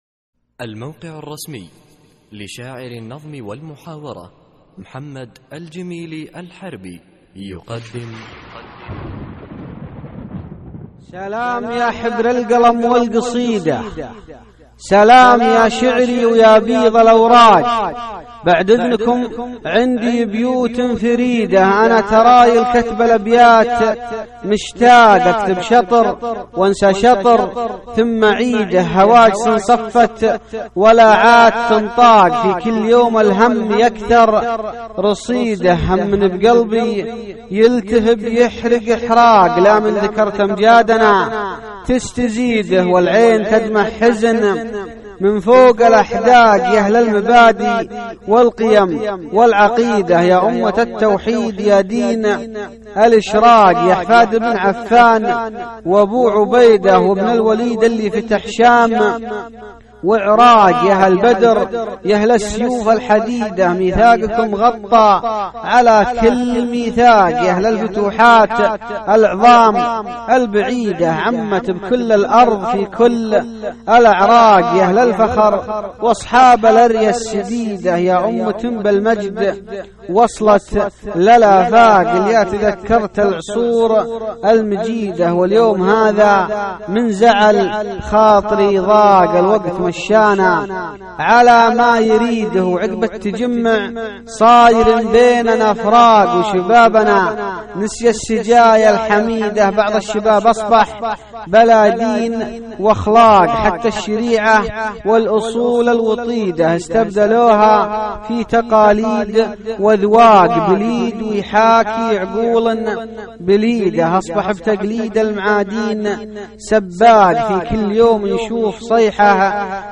القصـائــد الصوتية
اسم القصيدة : يا أمة التوحيد ~ إلقاء